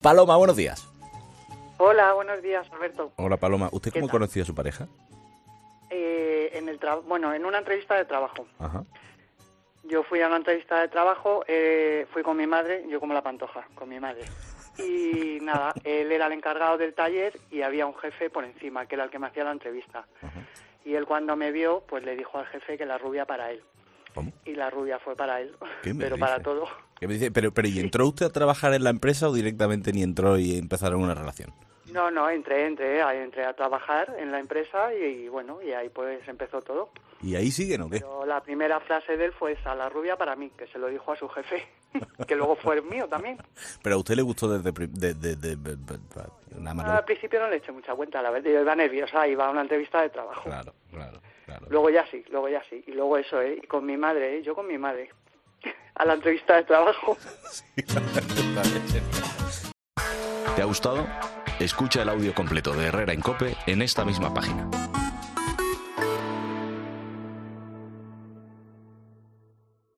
Una oyente de Herrera en COPE desvela cómo y con quién conoció a su marido